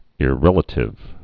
(ĭ-rĕlə-tĭv)